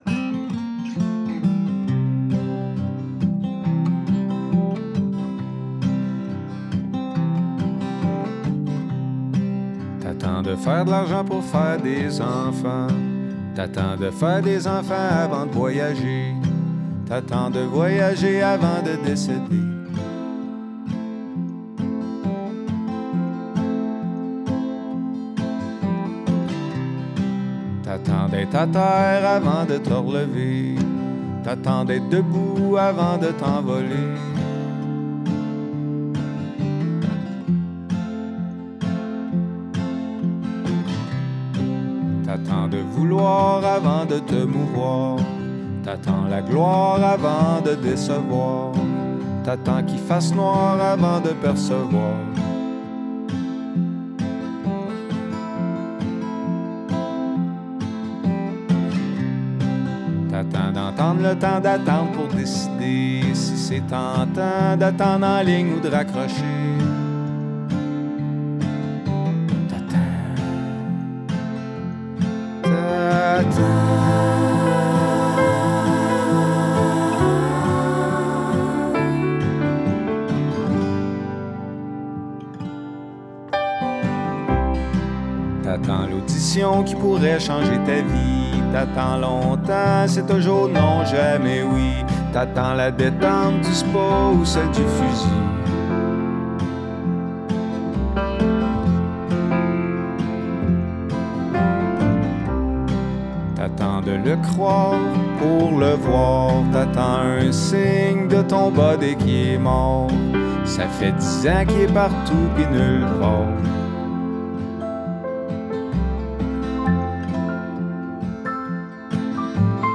Guitare, piano & voix
Contrebasse
Pas de suremballage, une économie d’accords et d’effets